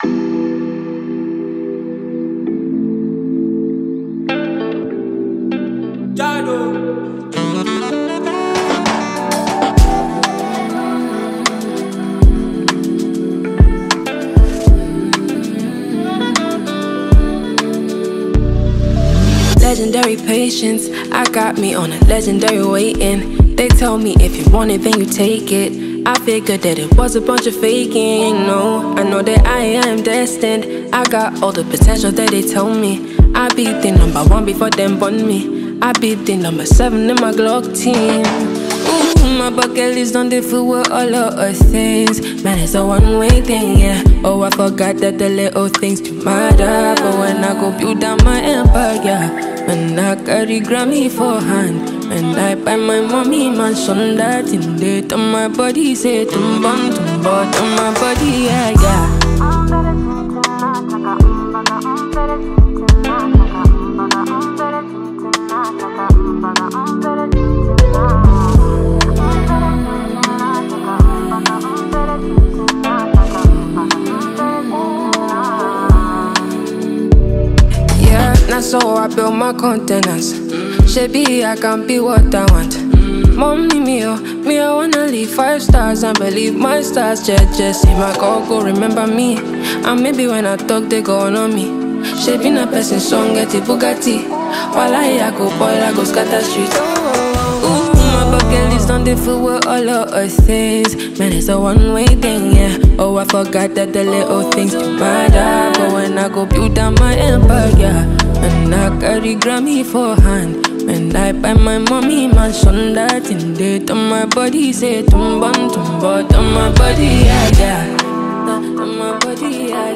Multi-talented Nigerian Female Songstress nd producer